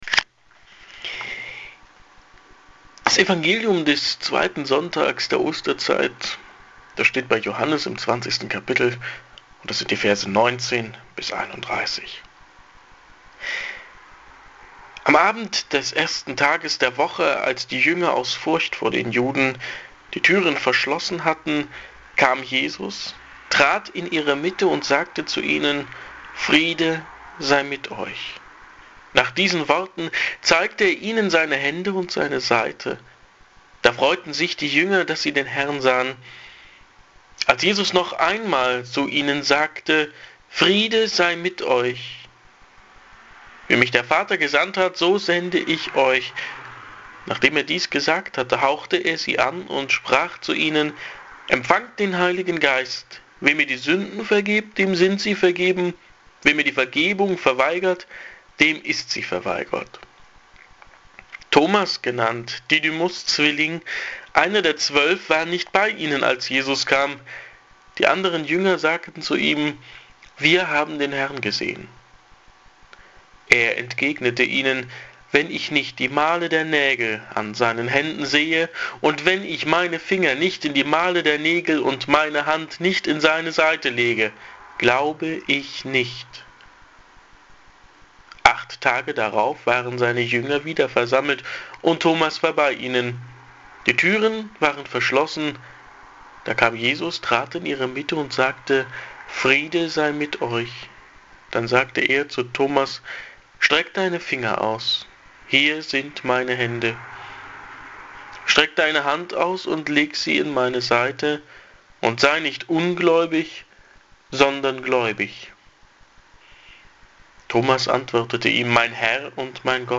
Predigt zum Weißen-Sonntag
hier-klickt-die-predigt.mp3